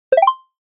SE（アイテムゲット レベルアップ）
ピロリ。ピロリン。